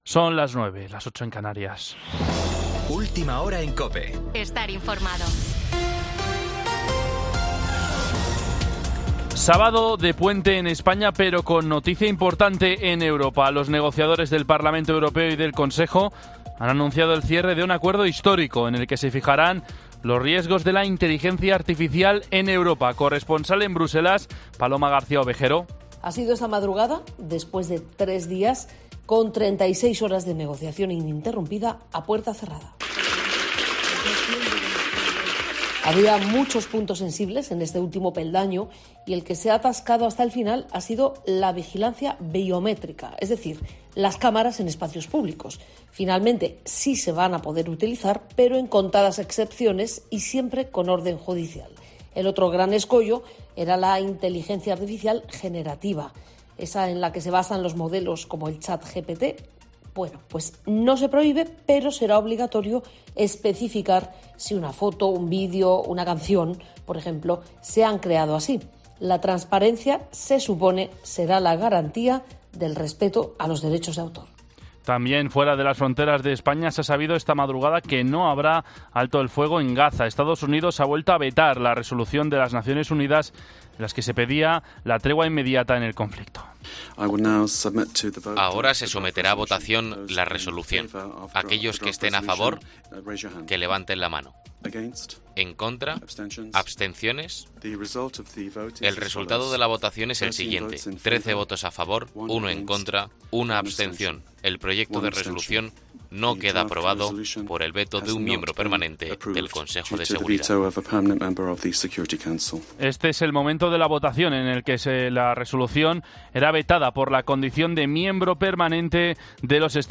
Boletín 09.00 horas del 9 de diciembre de 2023